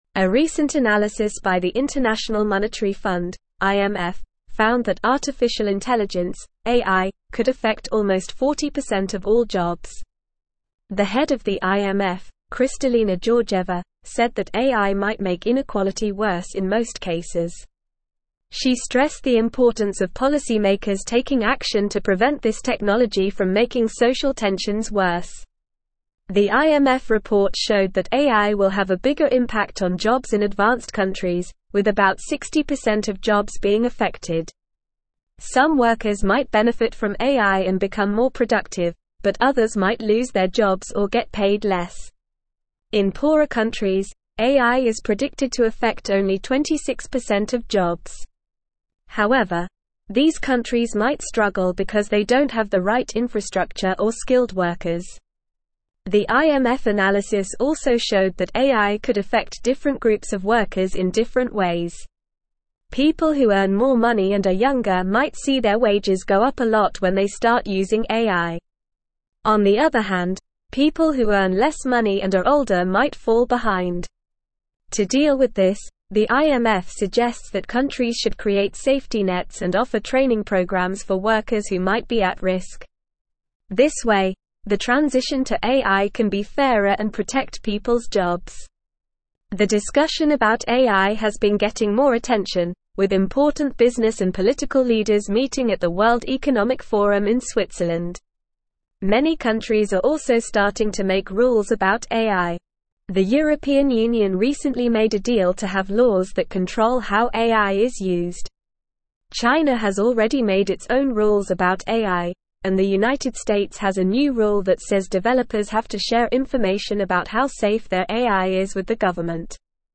Normal
English-Newsroom-Upper-Intermediate-NORMAL-Reading-AIs-Impact-on-Jobs-and-Inequality-IMF-Analysis.mp3